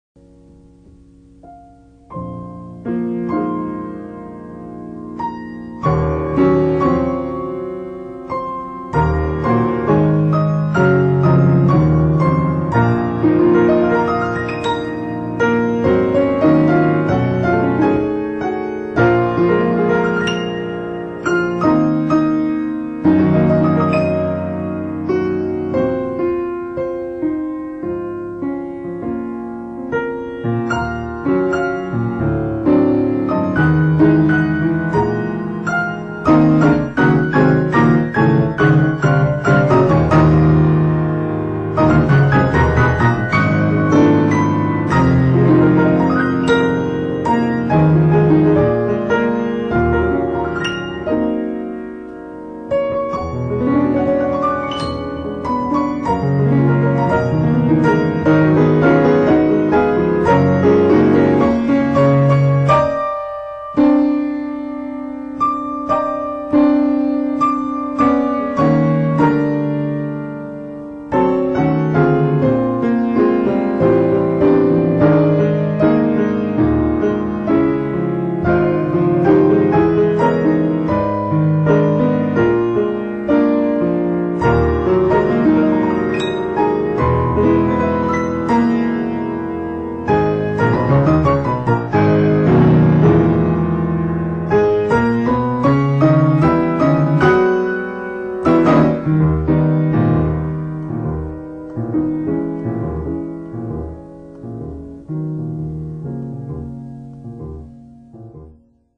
on two reel-to-reel tapes using state-of-the-art equipment